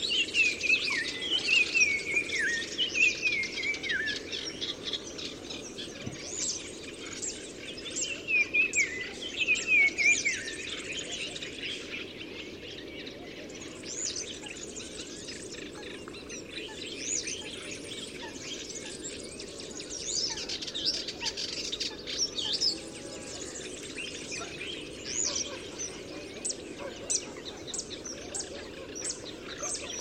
Photos de Tchagra à tête noire - Mes Zoazos
tchagra.mp3